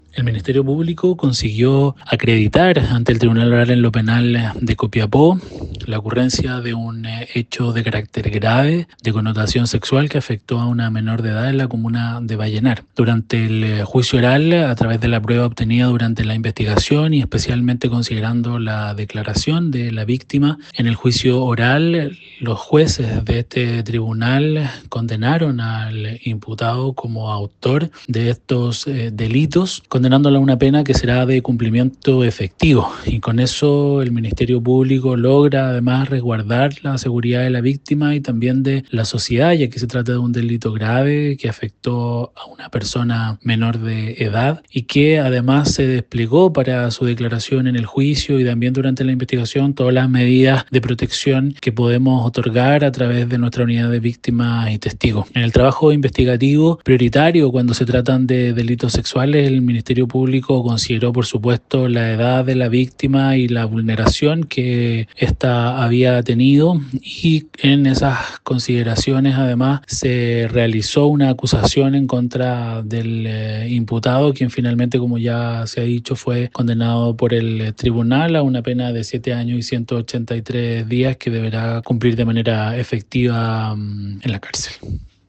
AUDIO-FISCAL-GUILLERMO-ZARATE.mp3